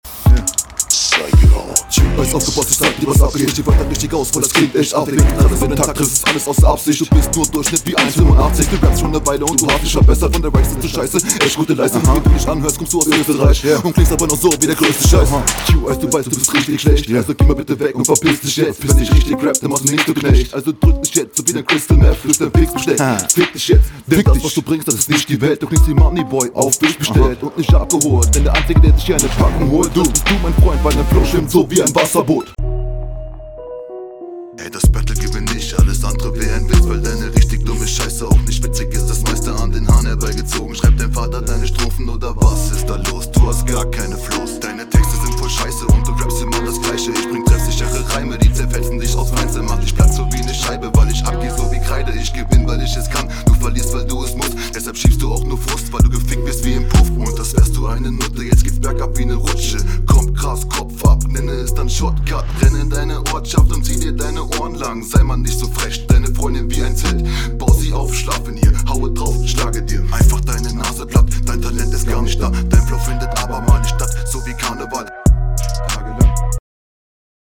unsaubere doubles kombiniert mit schnellem rap... keine gute idee. der beatwechsel kommt sehr komisch. auf …